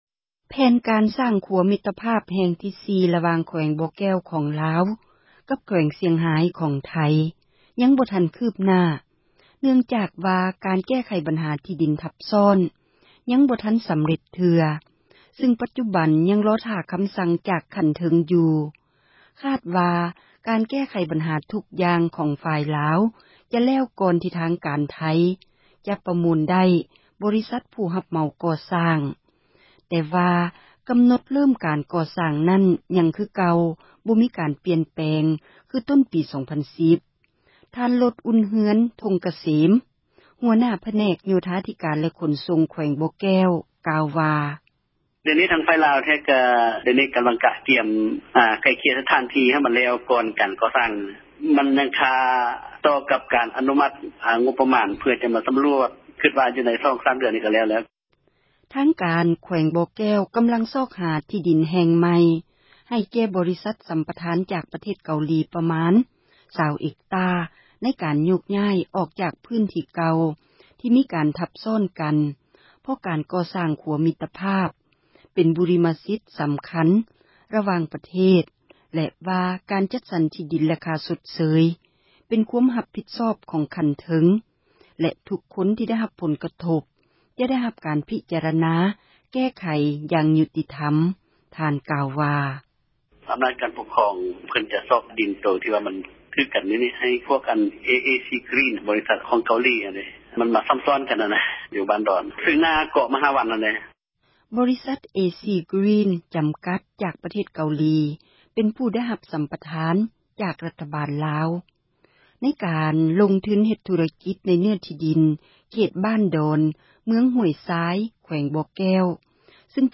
ທ່ານ ລົດອຸ່ນເຮືອນ ທົ່ງກະເສັມ ຫົວໜ້າຜແນກ ໂຍທາທິການ ແລະຂົນສົ່ງ ແຂວງບໍ່ແກ້ວ ກ່າວວ່າ: